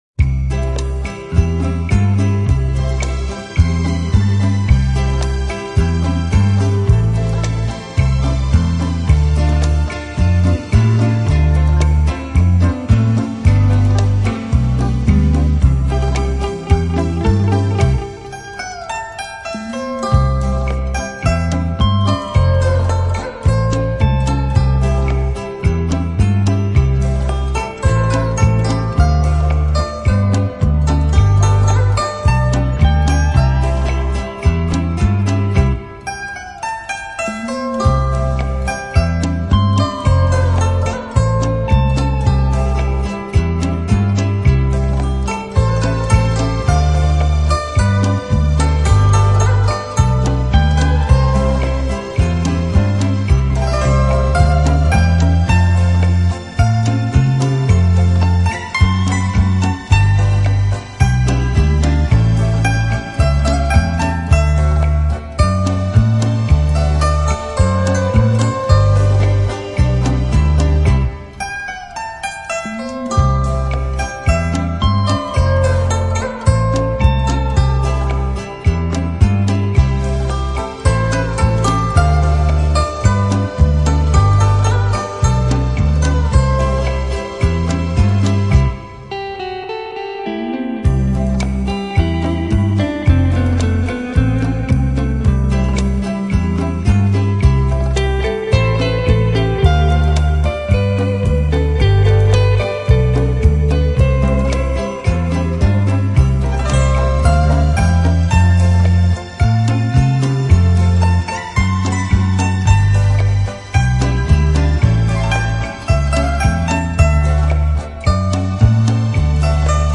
让那充满感情，气氛怀旧动人的优美乐曲，